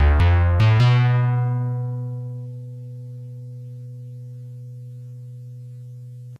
talking.ogg